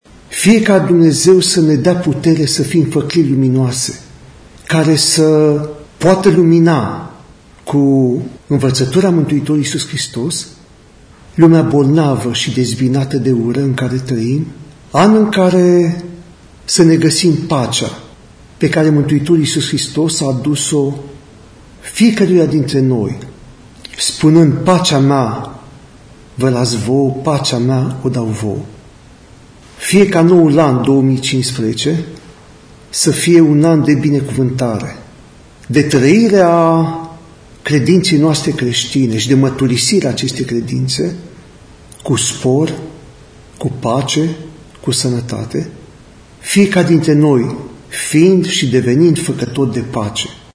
În mesajul de anul nou, Episcopul Sloboziei şi Călăraşilor, Preasfinţitul Părinte Vicenţiu, vorbeşte despre nevoia de comuniune şi pace între semeni:
audio Episcop Vincențiu